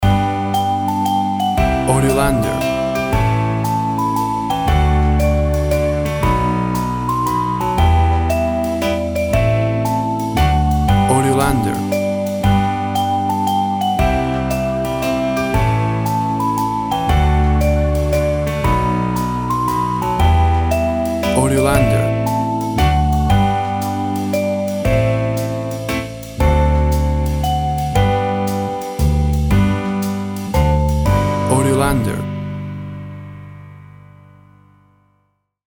WAV Sample Rate 16-Bit Stereo, 44.1 kHz
Tempo (BPM) 120